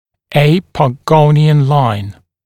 [eɪ-pɔ’gəunɪən laɪn][эй-по’гоуниэн лайн]линия А-погонион